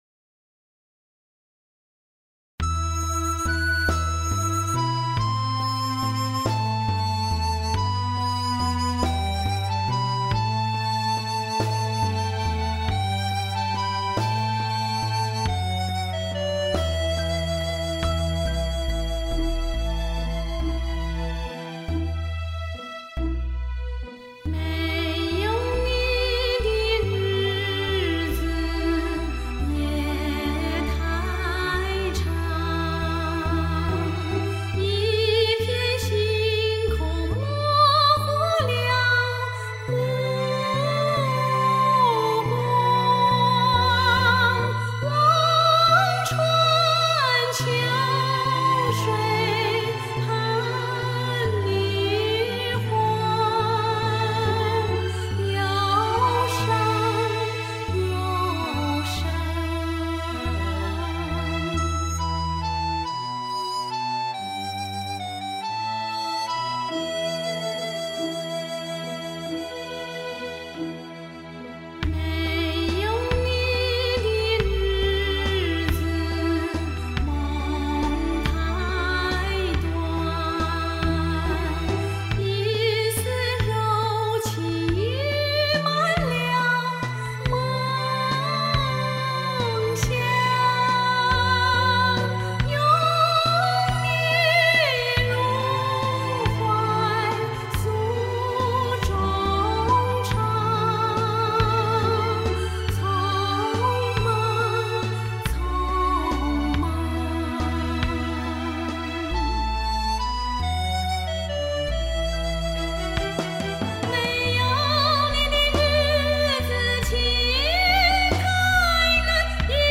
還在很短的時間裏對原配器的小提琴，貝司聲部做了修改，並重新編寫了大提琴聲部，使拌奏更加豐富。